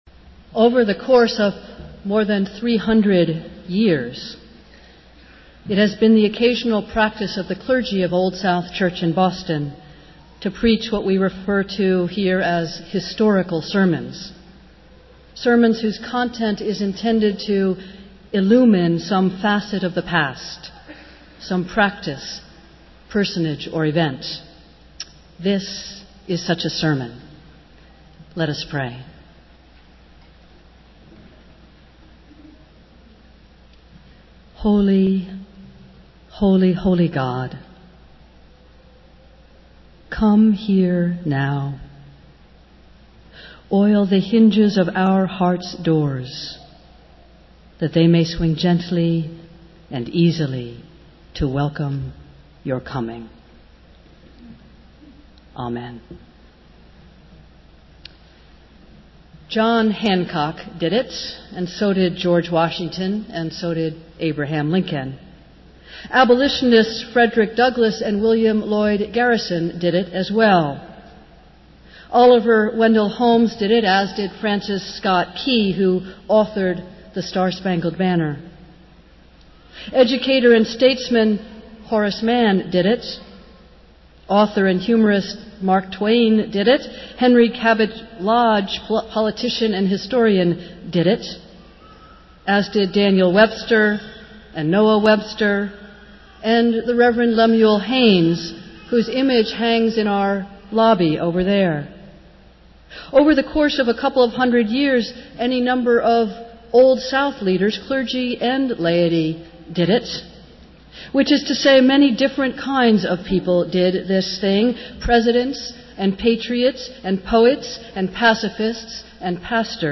Festival Worship - Sixth Sunday after Pentecost